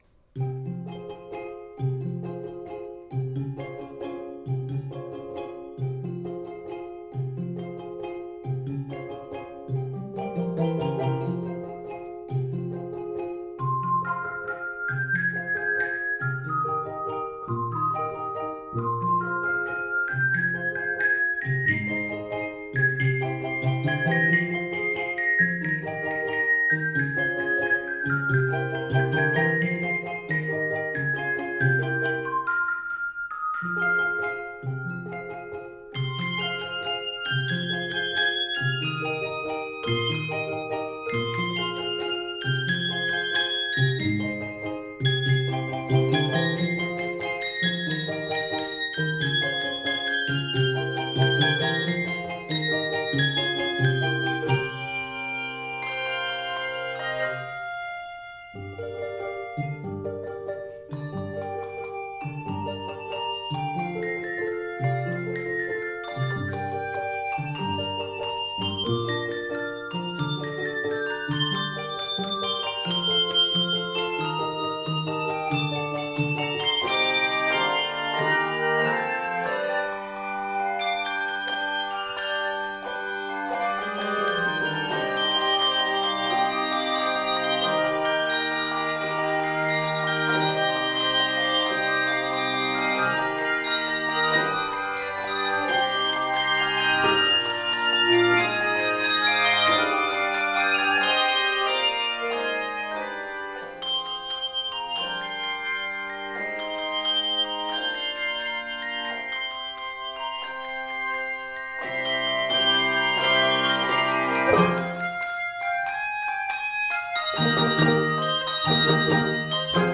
Keys of f minor, g minor, and c minor.
Octaves: 3-6